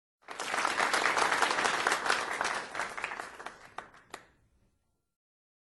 Short Applause Sound Effect-[www_flvto_com].mp3